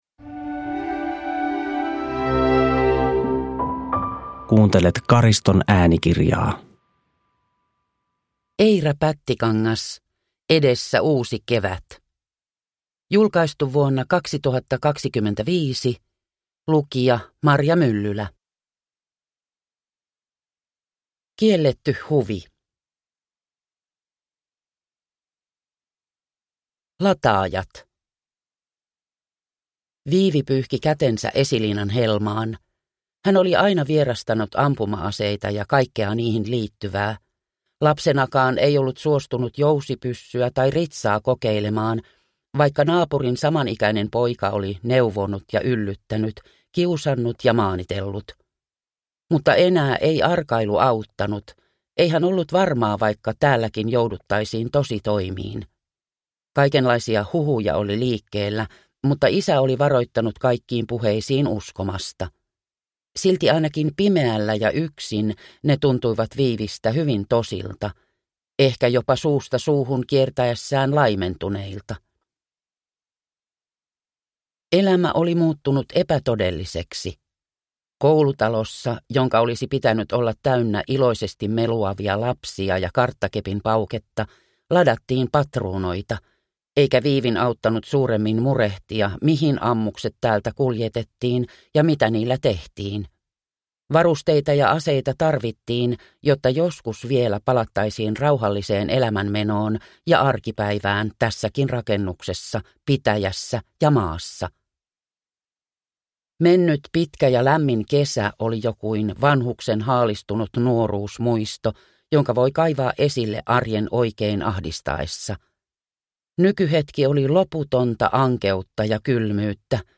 Edessä uusi kevät (ljudbok) av Eira Pättikangas